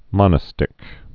(mŏnə-stĭk)